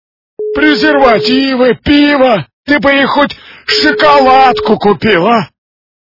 При прослушивании Говорящий телефон - Презервативы, пиво, ты хоть бы шоколадку купил качество понижено и присутствуют гудки.